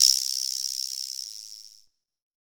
9VIBRASLAP.wav